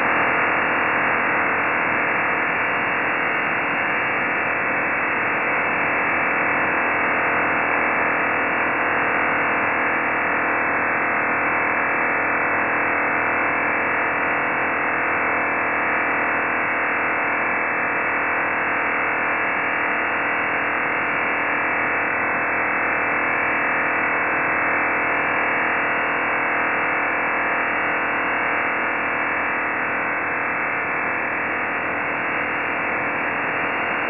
Начало » Записи » Радиоcигналы на опознание и анализ
Псевдо OFDM